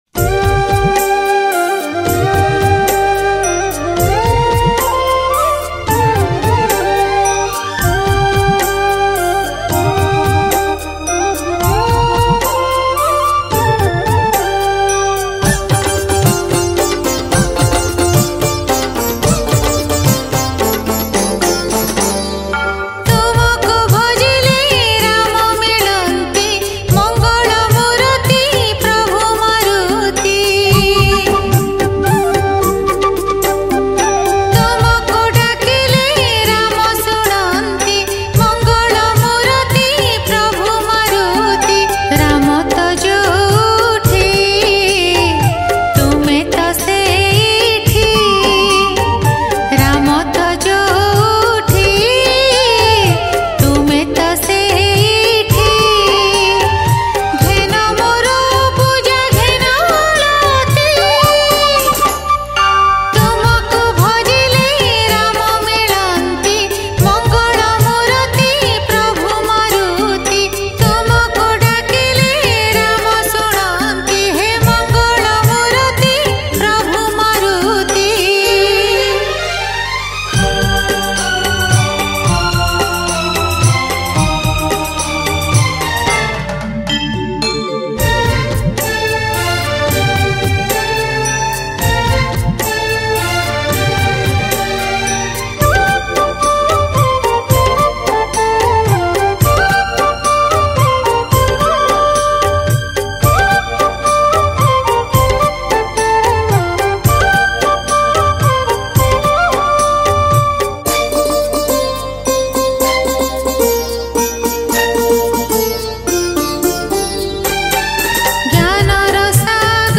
Pana Sankarati Special Bhajan Songs Download